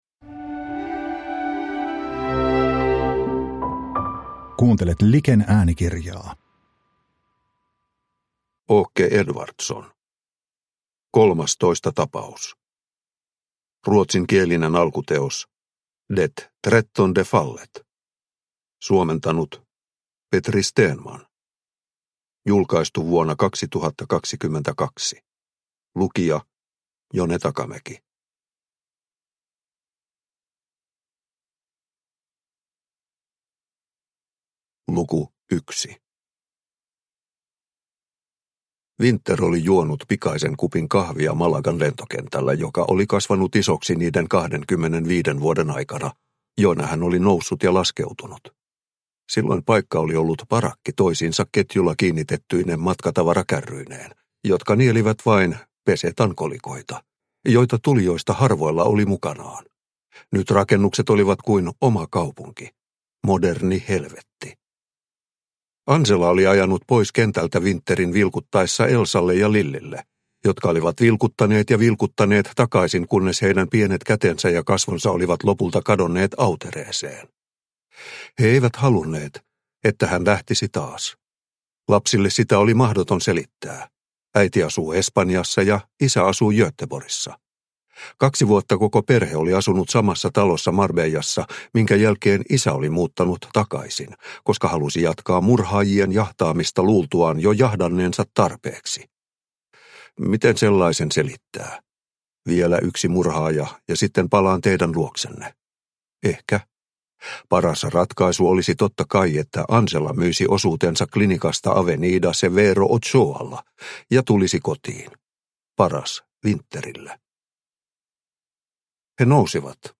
Kolmastoista tapaus – Ljudbok – Laddas ner